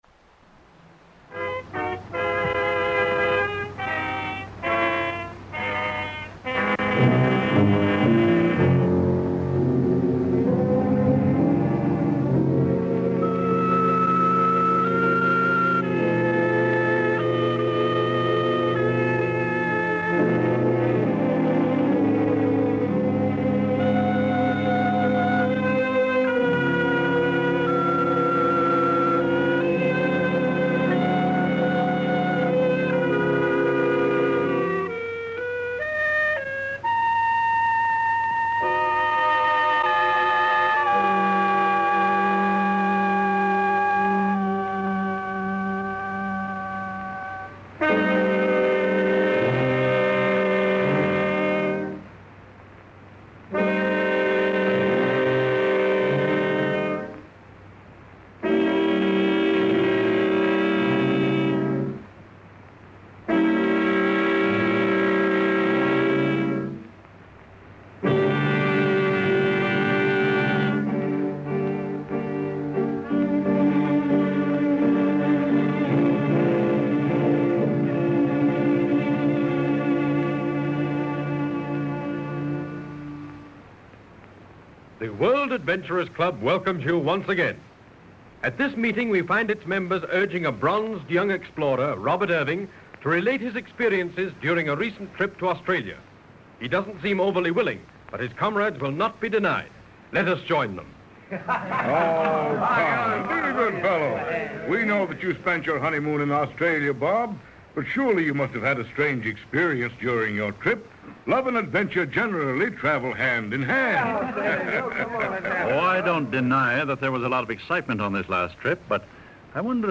Let's take a trip back to the golden age of radio with the World Adventurers Club and its thrilling episode "The 25 Kaditcha."